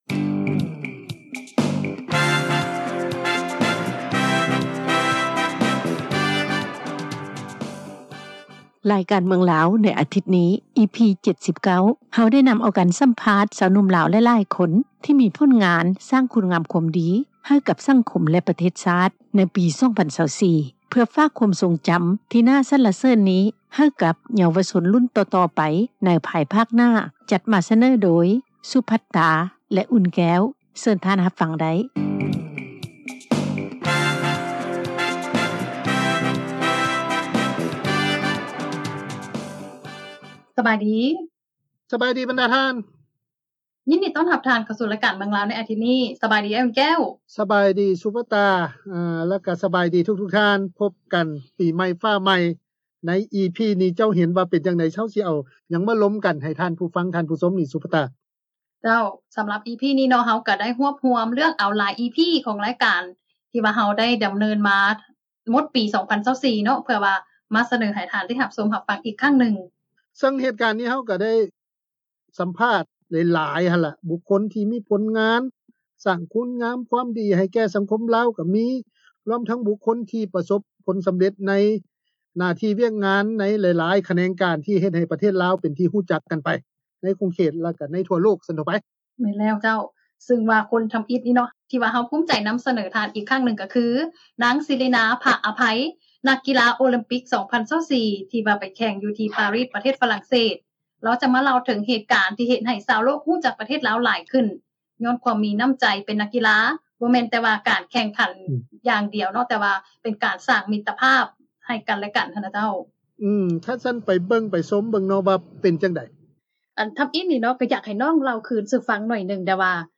ລາຍການ ເມືອງລາວ ໃນອາທິດນີ້ EP-79 ເຮົາໄດ້ນຳເອົາ ການສຳພາດ ຊາວໜຸ່ມລາວ ຫຼາຍໆຄົນ ທີ່ມີຜົນງານ ແລະ ສ້າງຄຸນງາມຄວາມດີ ໃຫ້ກັບສັງຄົມລາວ ໃນຮອບປີ 2024 ເພື່ອແບ່ງປັນປະສົບການ ທີ່ໜ້າສັນລະເສີນນີ້ ໃຫ້ກັບເຍົາວະຊົນ ລຸ້ນຕໍ່ໆໄປ ໃນພາຍພາກໜ້າ.